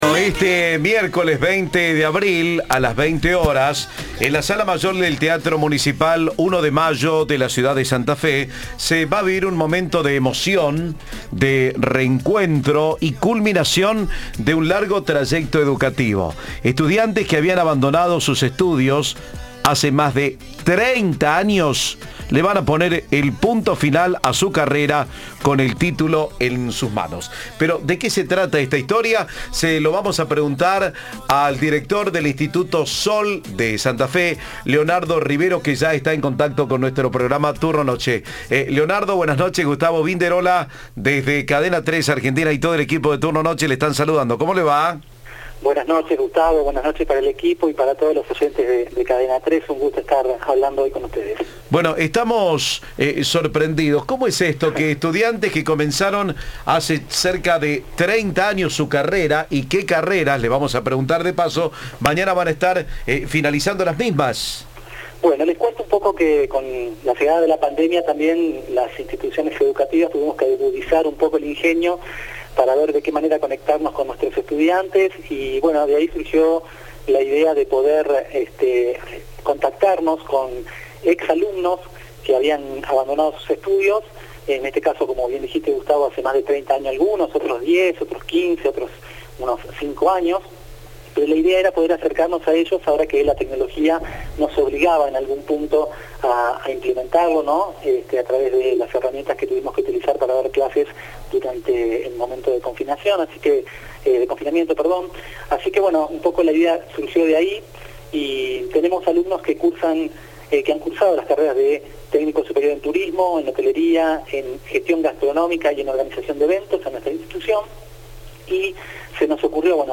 Entrevista de "Turno Noche".